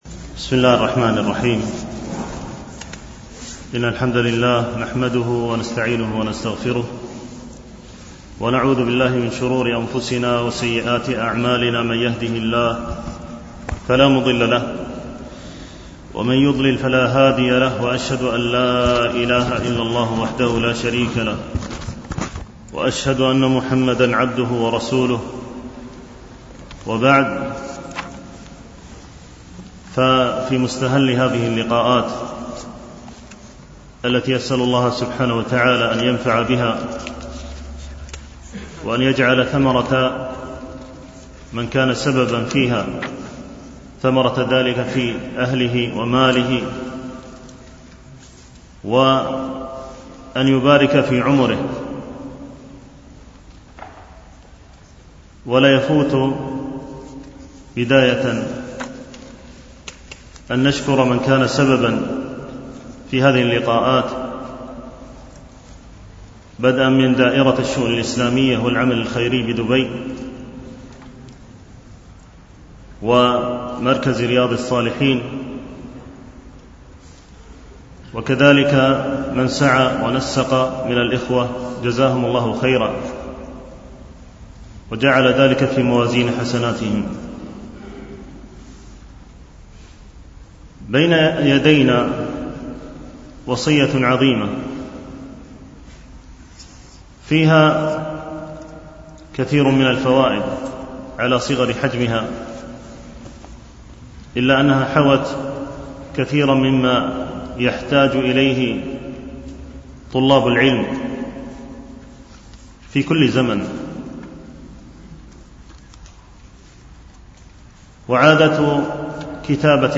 شرح وصية العلامة عبد الرحمن المعلمي لتلميذه ـ الدرس الأول
دروس مسجد عائشة (برعاية مركز رياض الصالحين ـ بدبي)